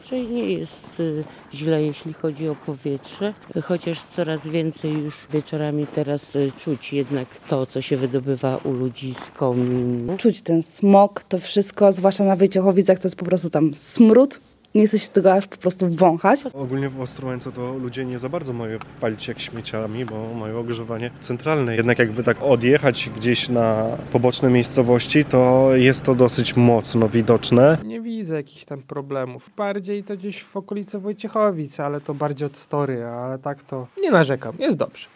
O jakość powietrza w Ostrołęce zapytaliśmy mieszkańców. Większość zapytanych przez nas osób nich nie ma zastrzeżeń.